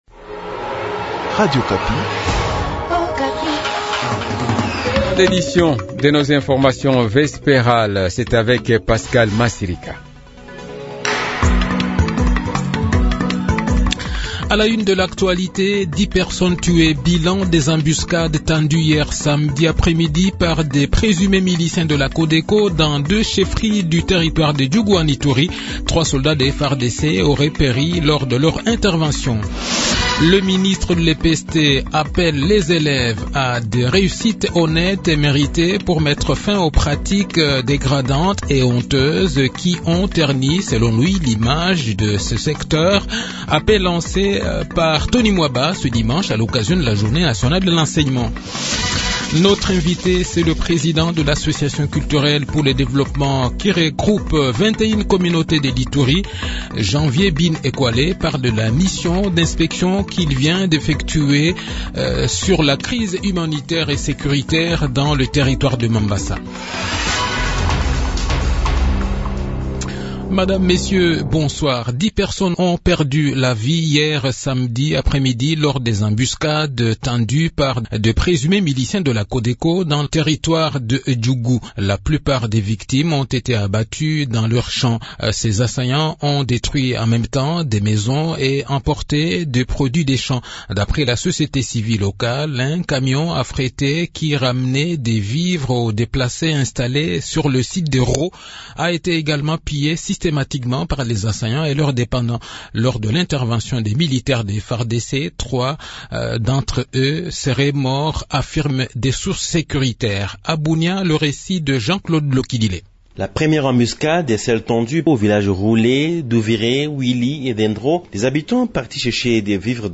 Journal Soir
Le journal de 18 h, 30 Avril 2023